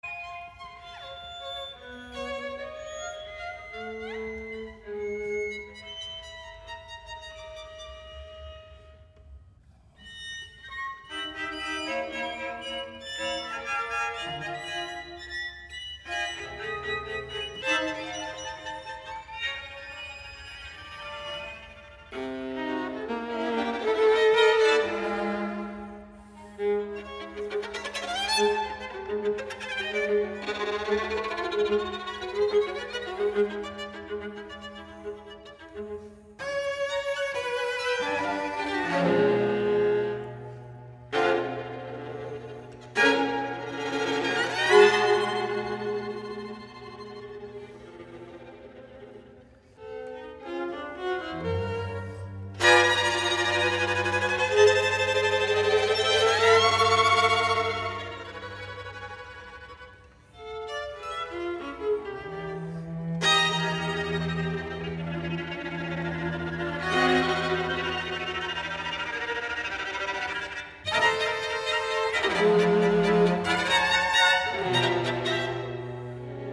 傳奇錄音首次以CD載體發行
古典音樂